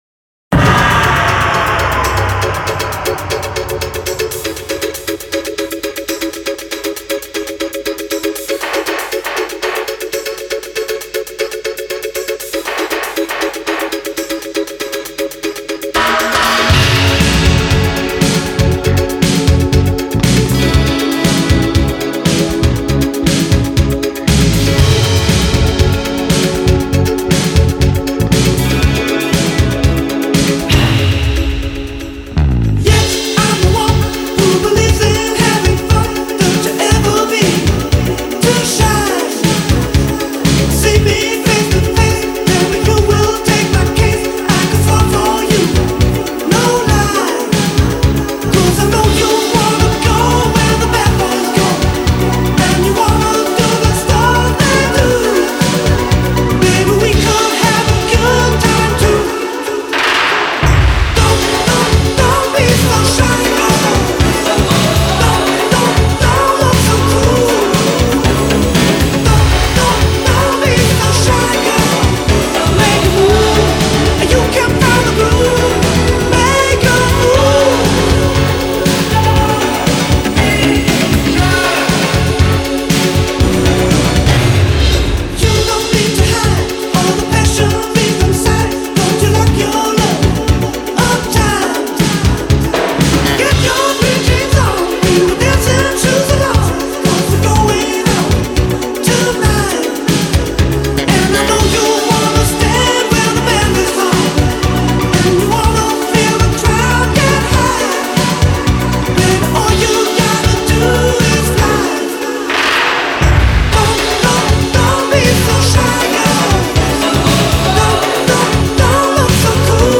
Жанр: Pop/Synth-pop